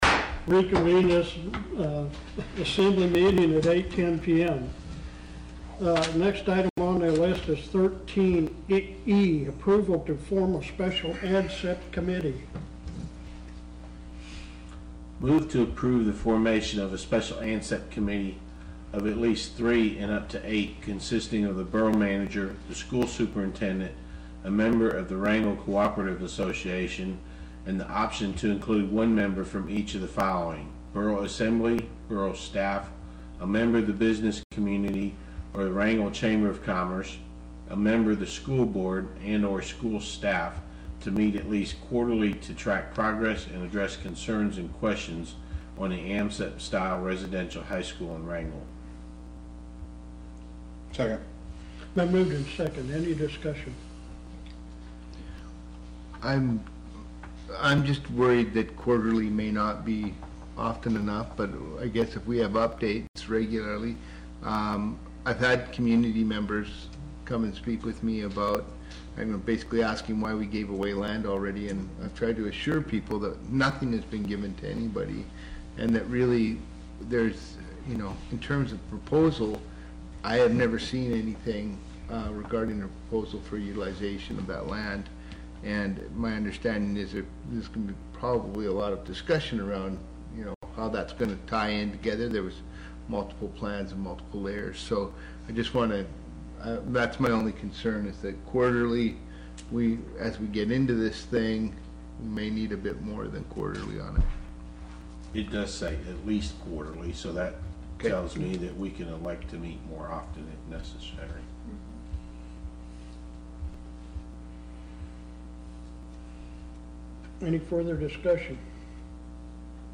Wrangell Borough Assembly meeting on July 26, 2016.
July 26, 2016 – 7:00 p.m. Location: Assembly Chambers, City Hall 1.